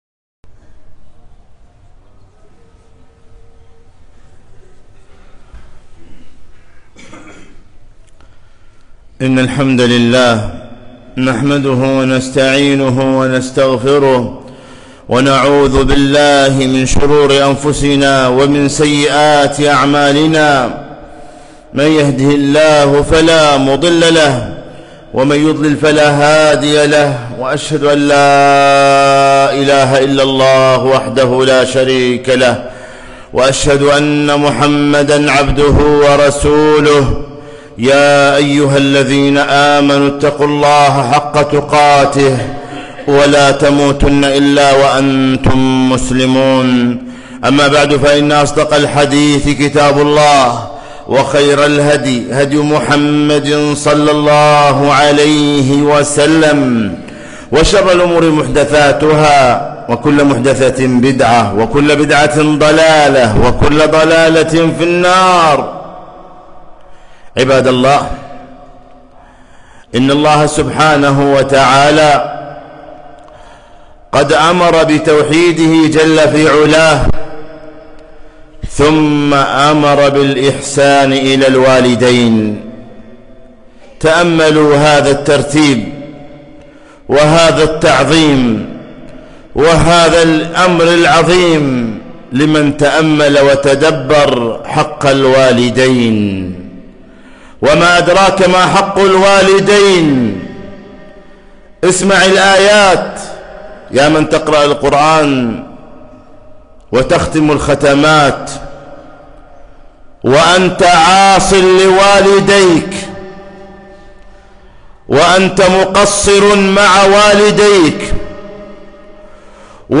خطبة - بر الوالدين مشروع لا يخسر